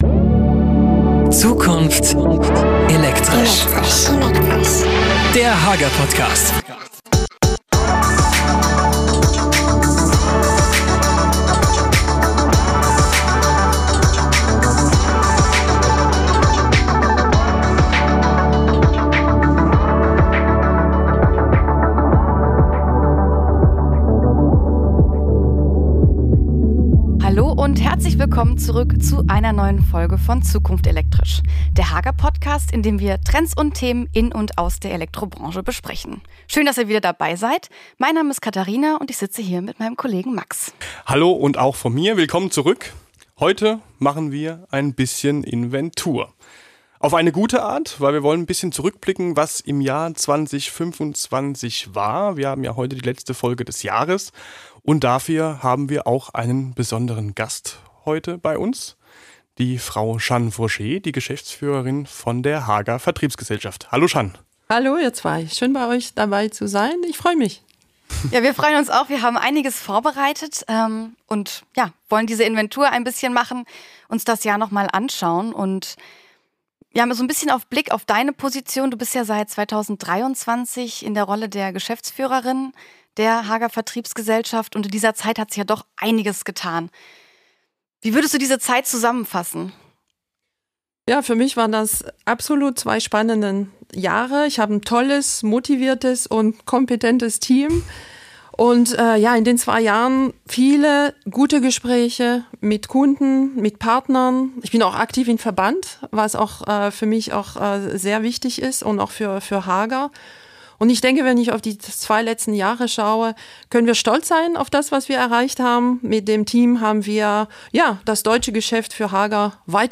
Plus: Mitarbeiterstimmen, Kundenkommentare und ganz persönliche Festtagsgrüße.